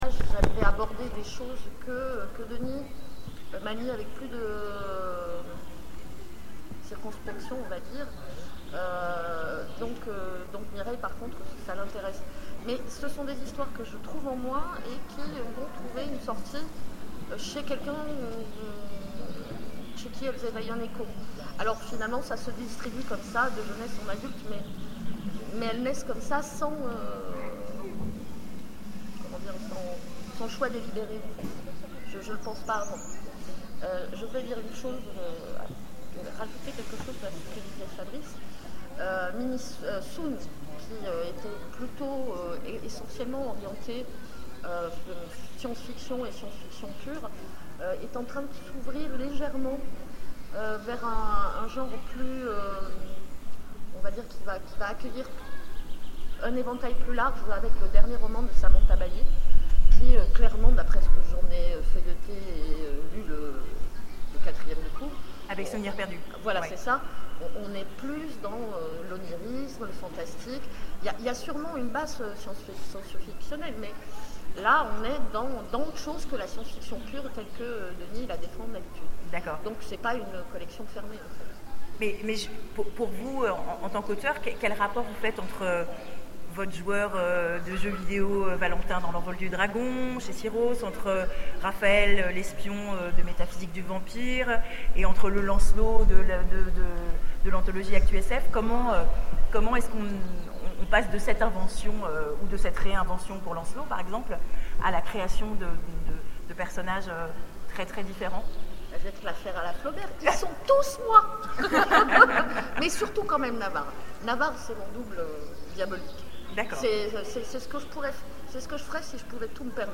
Imaginales 2014 : Conférence Raconteurs d'histoires
(attention, malheureusement la conférence est un peu tronquée au début...).